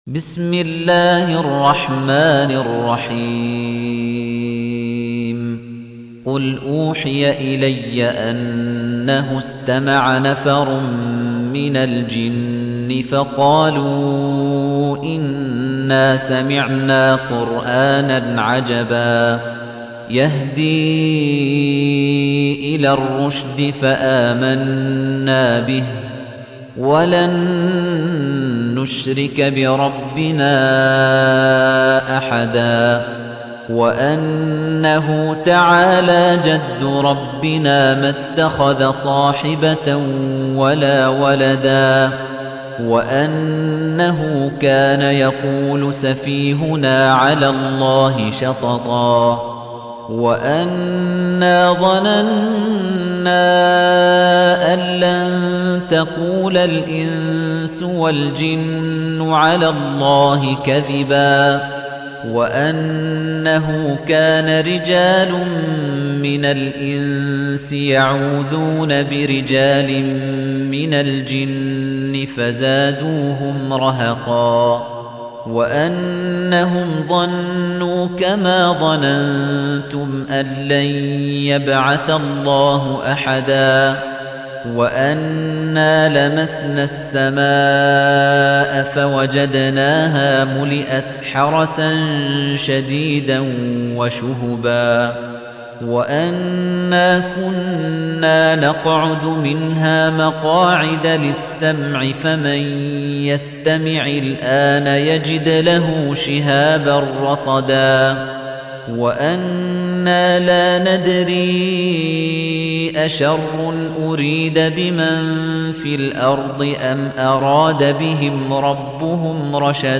تحميل : 72. سورة الجن / القارئ خليفة الطنيجي / القرآن الكريم / موقع يا حسين